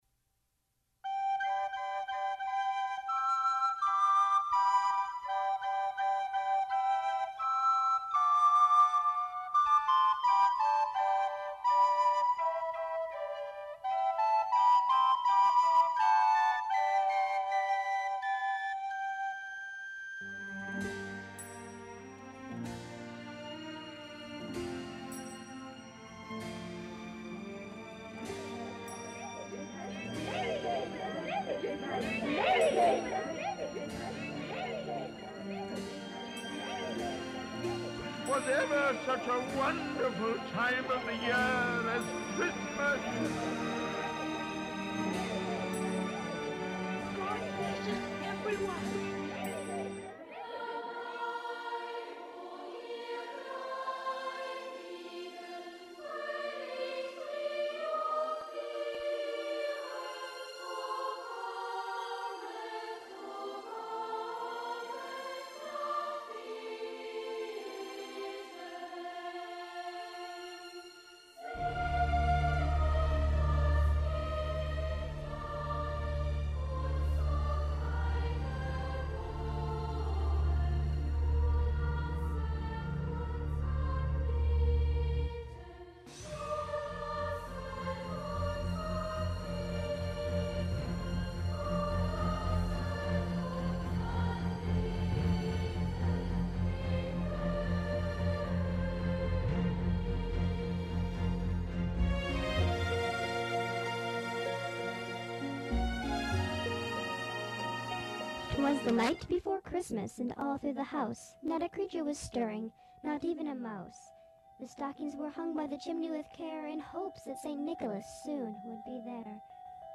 Whistle
Voice